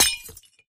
glass2.ogg